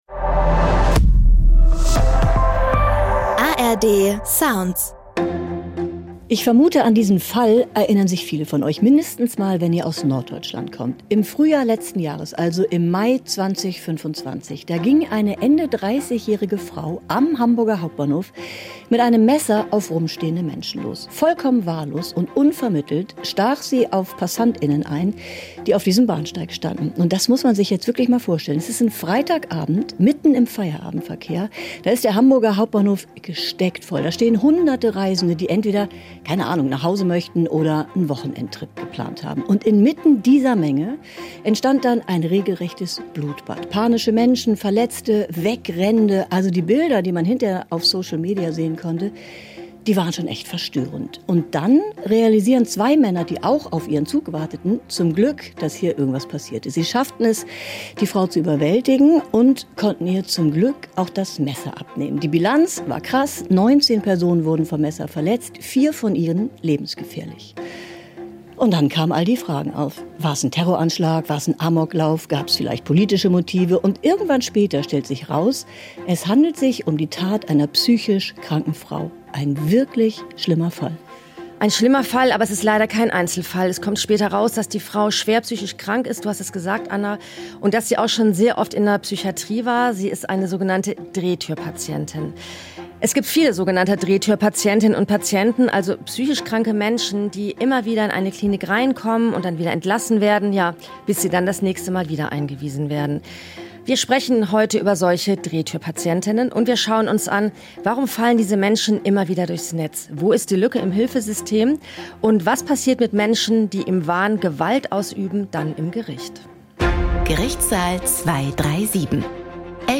Vorschau auf die Folge